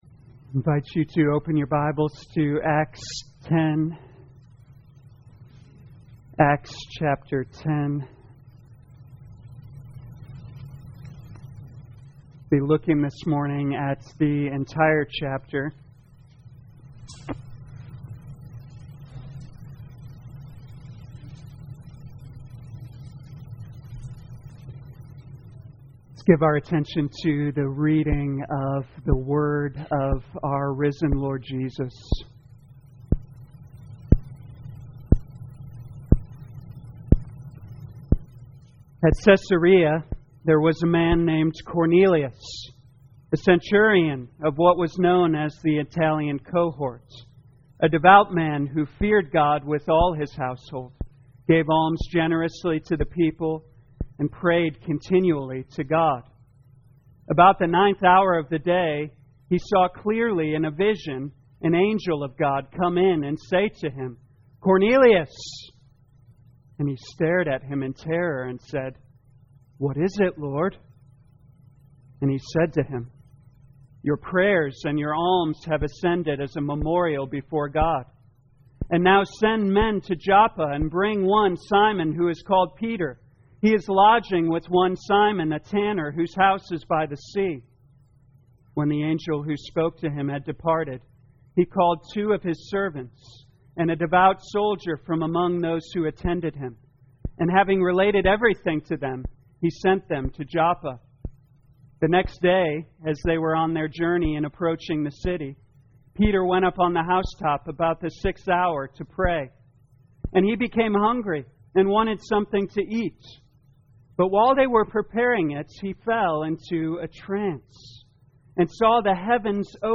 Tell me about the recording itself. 2021 Acts Morning Service Download